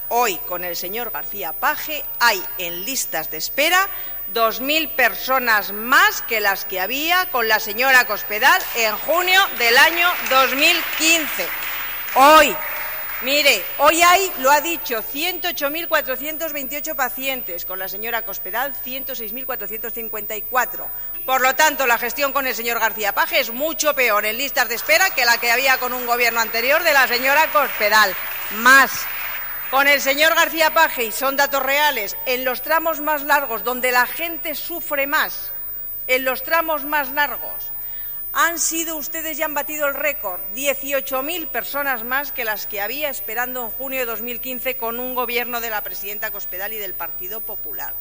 Durante su intervención en el Debate General, relativo a las listas de espera quirúrgica del SESCAM, celebrado en el Pleno de las Cortes, ha recordado que a día de hoy las listas de espera son un engaño y no responden a la realidad ya que todavía no se han hecho públicos los datos de cirugía menor.